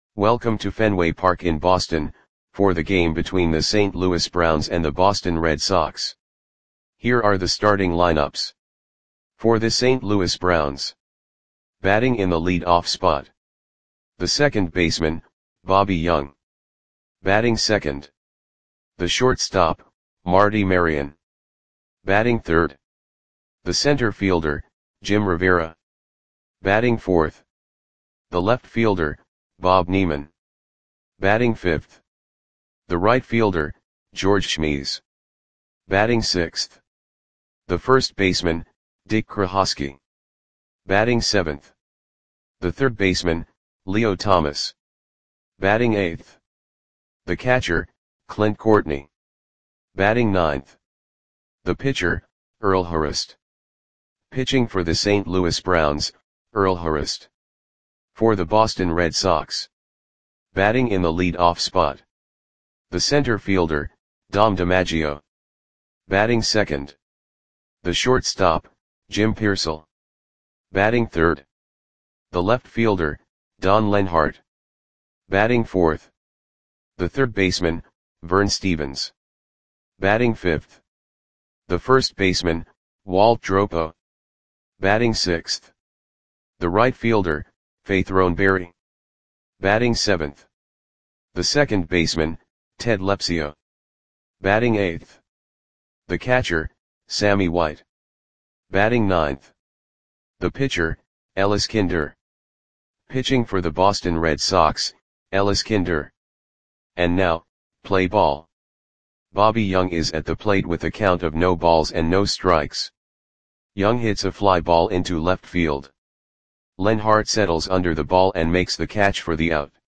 Audio Play-by-Play for Boston Red Sox on May 3, 1952
Click the button below to listen to the audio play-by-play.